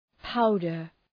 Προφορά
{‘paʋdər}